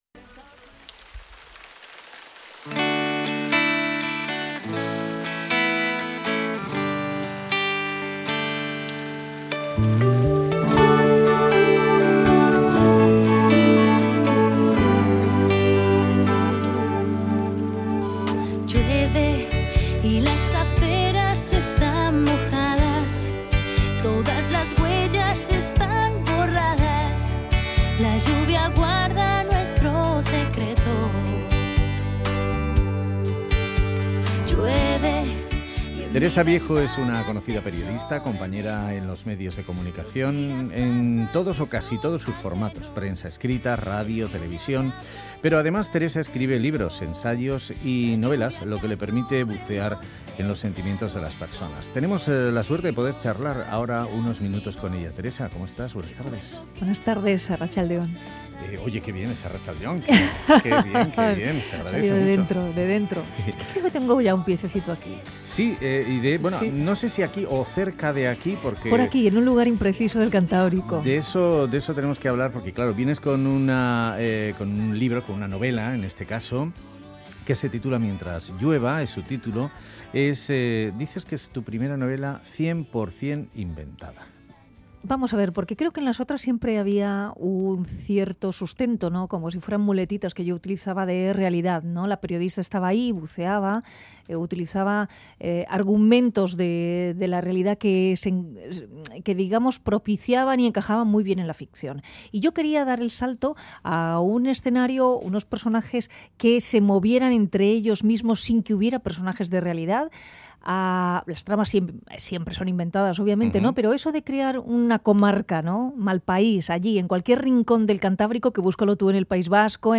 'Mientras llueva' es el nuevo libro de Teresa Viejo, que cuenta la historia de una mujer que decide huir de su vida. Hablamos con ella sobre volver a empezar.